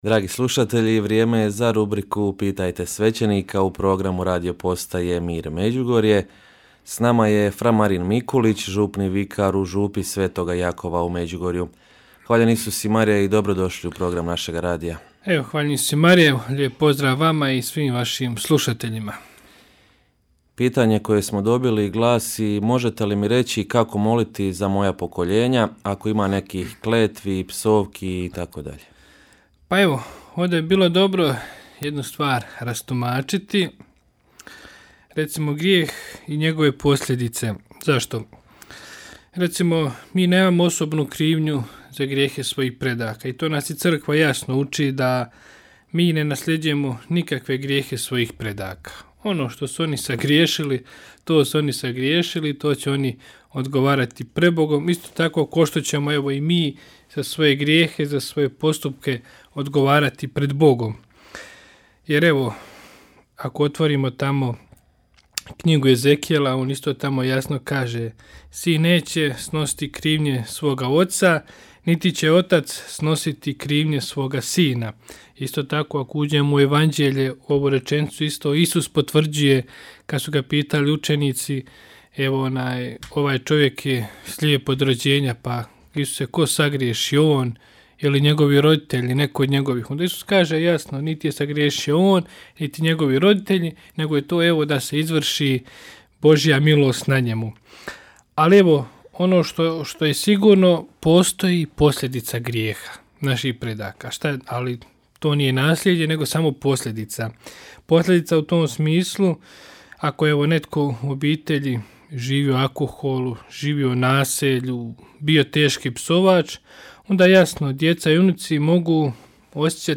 Rubrika ‘Pitajte svećenika’ u programu Radiopostaje Mir Međugorje je ponedjeljkom od 8 sati i 20 minuta, te u reprizi ponedjeljkom navečer u 20 sati i 15 minuta. U njoj na pitanja slušatelja odgovaraju svećenici, suradnici Radiopostaje Mir Međugorje.